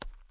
stone_3.ogg